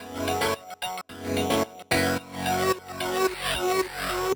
MonteCarlo_F#_110_FX.wav